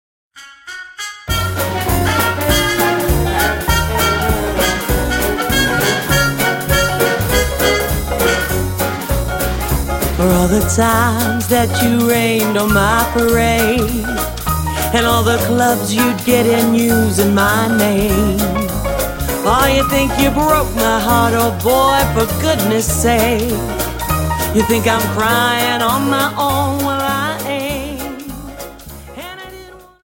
Dance: Quickstep